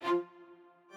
strings6_63.ogg